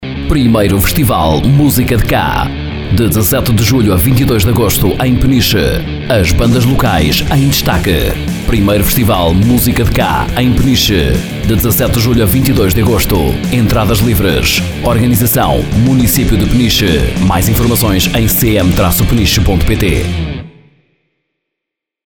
Spot 1º Festival "Música de Cá" - 102 FM Rádio (pdf)